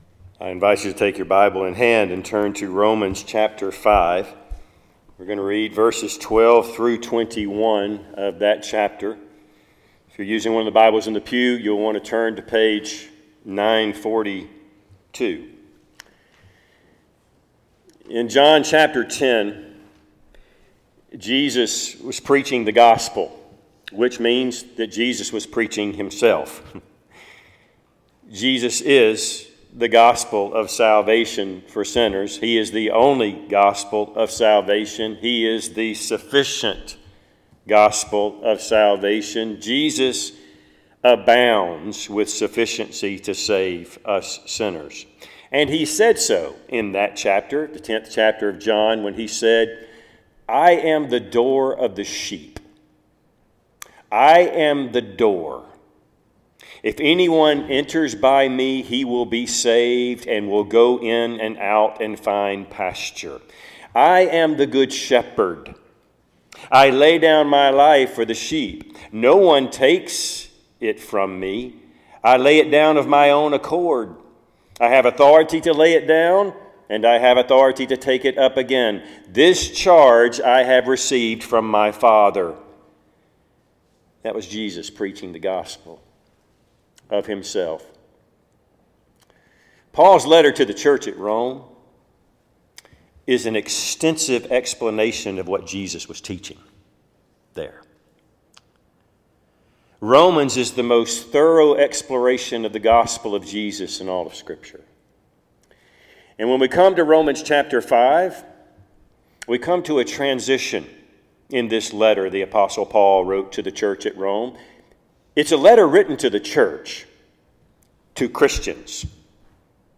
Passage: Romans 5:12-21 Service Type: Sunday AM